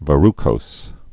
(və-rkōs) also ver·ru·cous (-kəs)